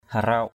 /ha-raʊ:ʔ/